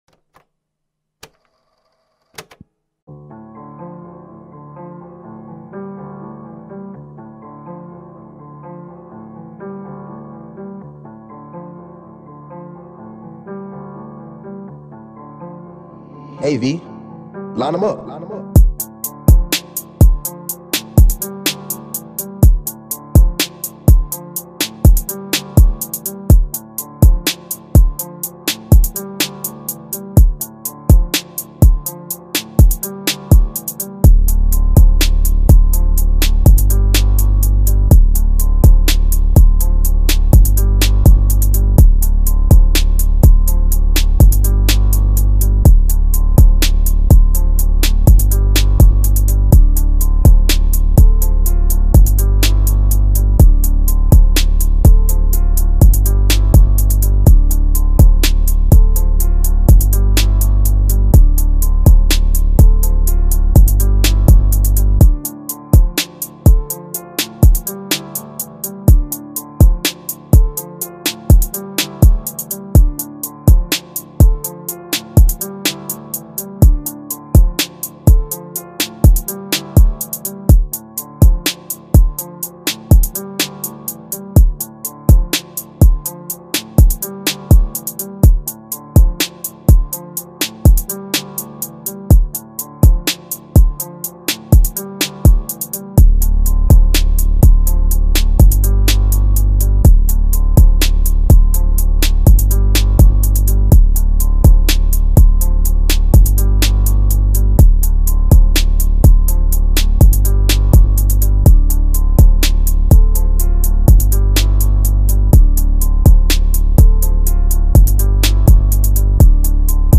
ژانر : ترپ مود : گنگ | فری استایل | چیل
گام : Gm
تمپو : 124